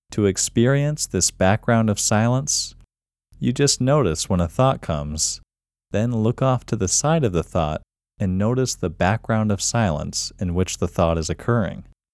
QUIETNESS Male English 6
The-Quietness-Technique-Male-English-6.mp3